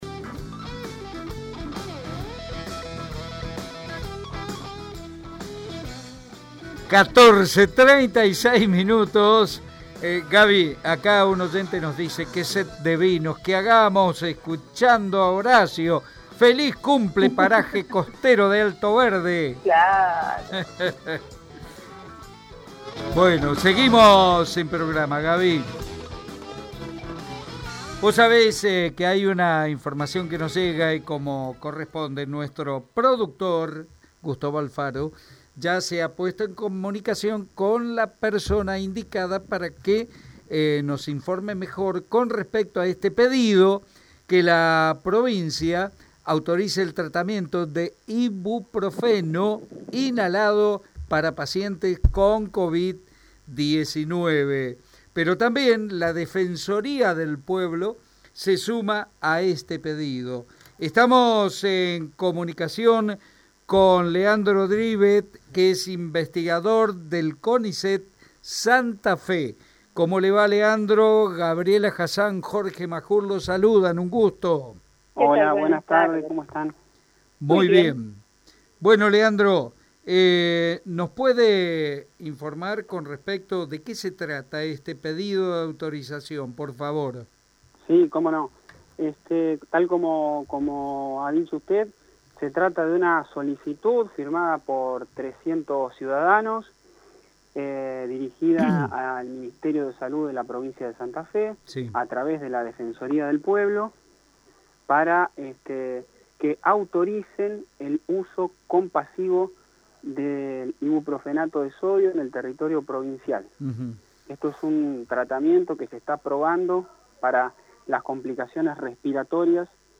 ABRIENDO-PUERTAS-INVESTIGADOR-CONICET-SOBRE-IBUPROFENO-INHALADO.mp3